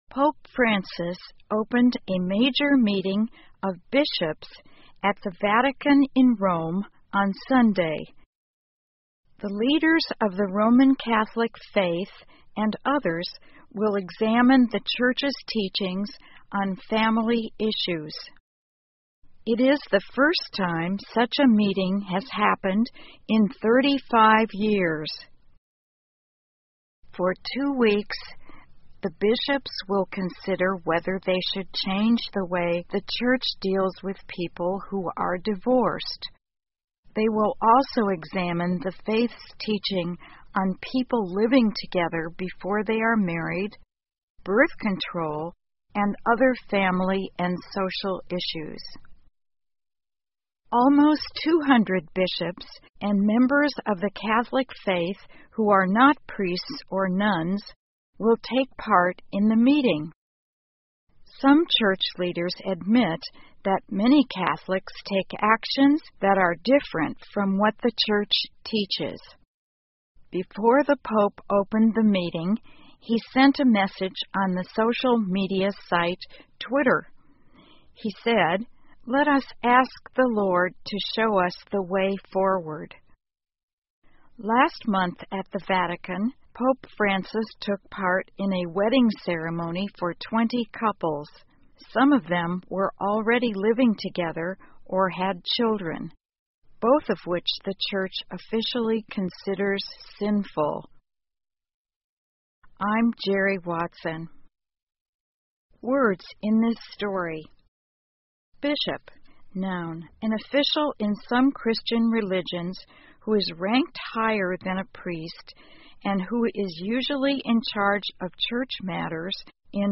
VOA慢速英语2014 弗朗西斯教皇与主教审查天主教教义 听力文件下载—在线英语听力室